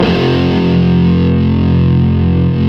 Index of /90_sSampleCDs/Roland - Rhythm Section/GTR_Distorted 1/GTR_Power Chords